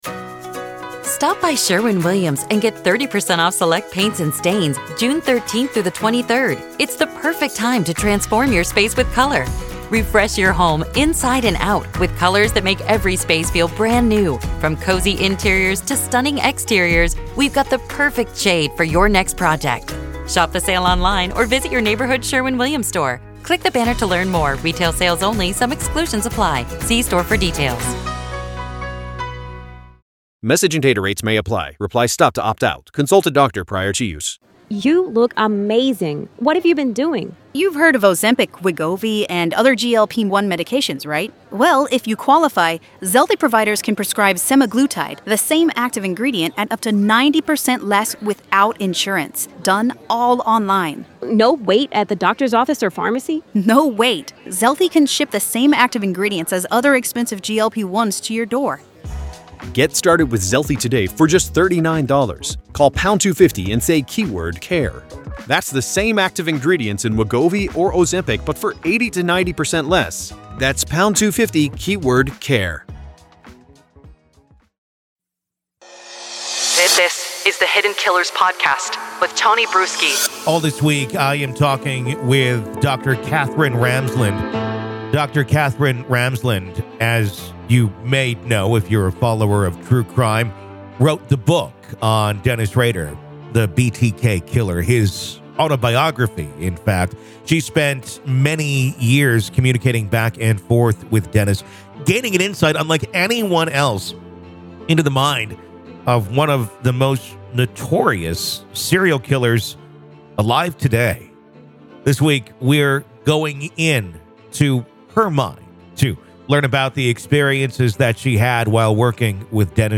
Dr. Katherine Ramsland Interview Behind The Mind Of BTK Part 5